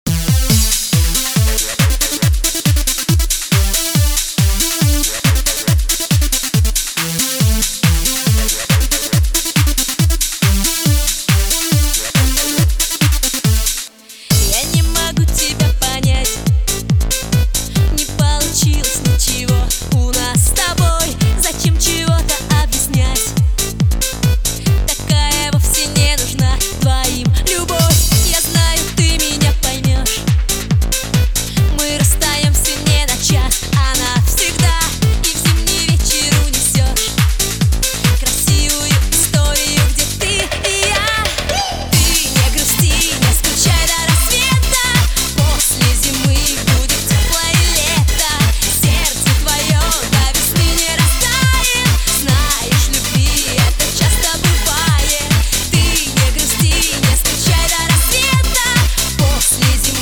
Pop
российская поп-группа.